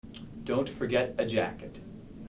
HAS-Corpus / Audio_Dataset /neutral_emotion /248_NEU.wav